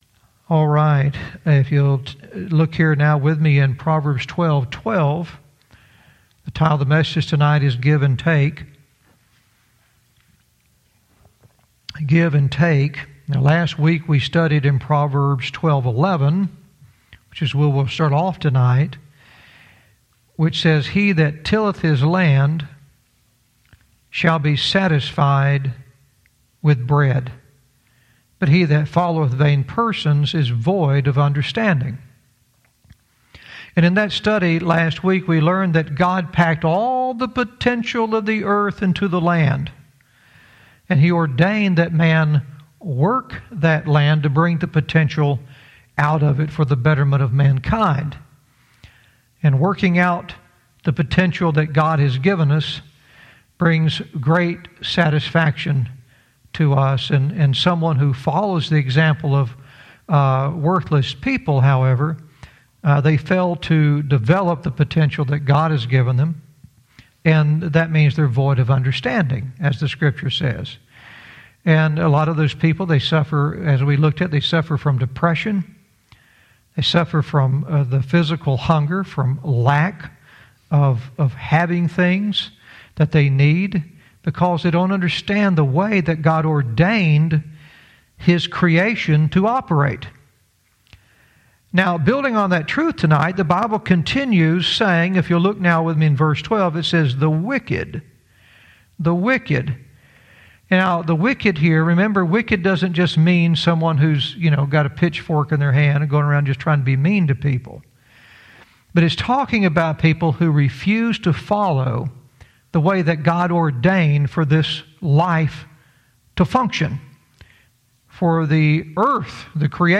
Verse by verse teaching - Proverbs 12:12 "Give and Take"